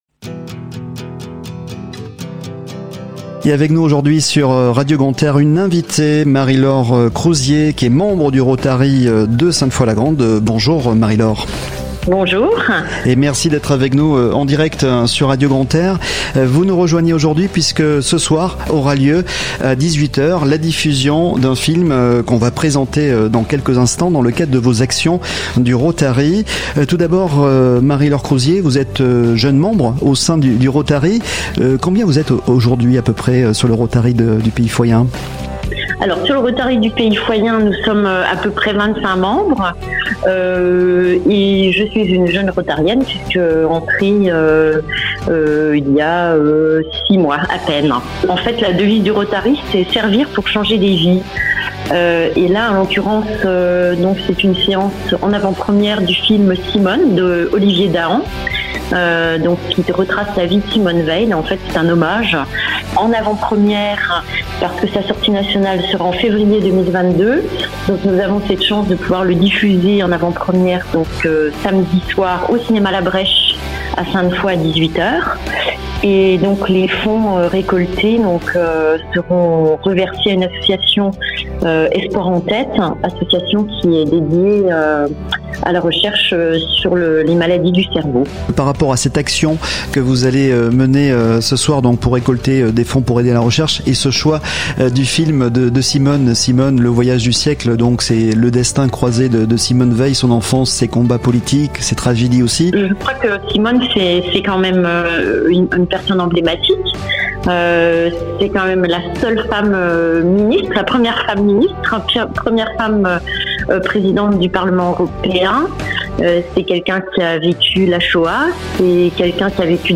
3. Les invités sur Radio Grand "R"